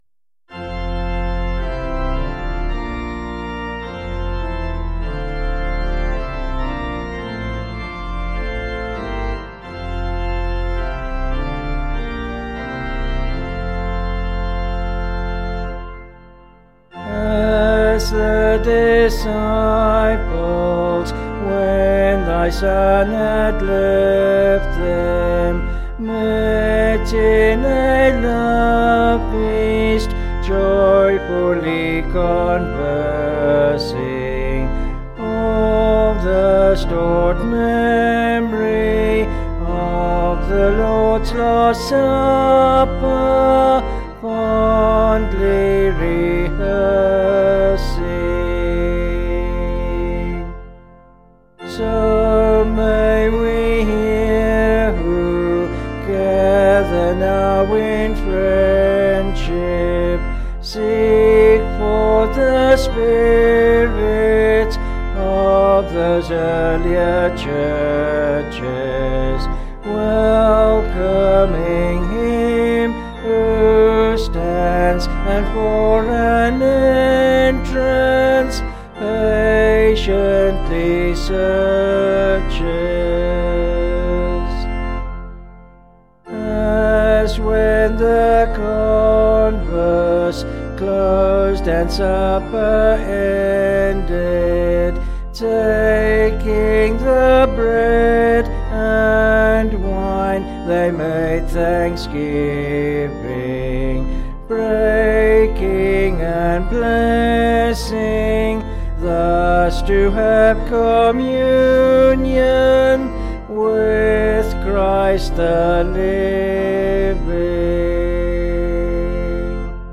Vocals and Organ   265.3kb Sung Lyrics